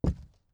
WoodFootsteps
ES_Walk Wood Creaks 19.wav